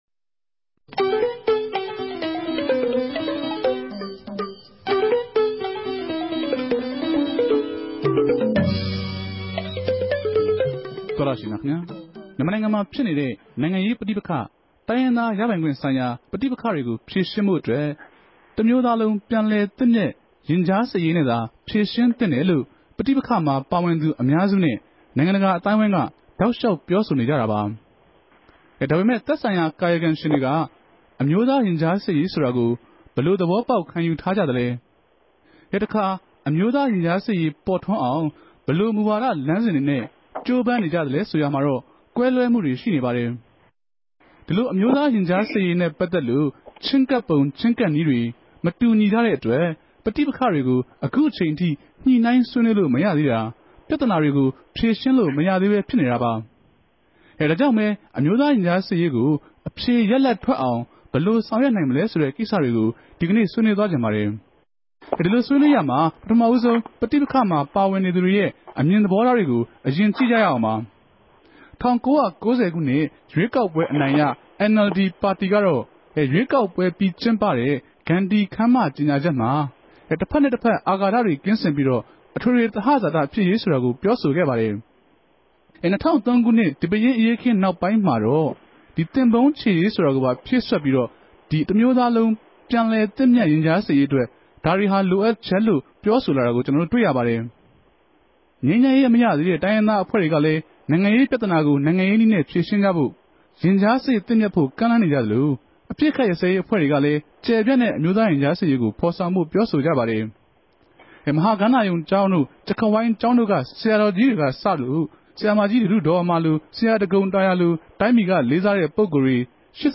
RFA ဝၝရြင်တန်႟ုံးခဵြပ် စတူဒီယိုထဲကနေ
တနဂဿေိံြ ဆြေးေိံြးပြဲစကားဝိုင်း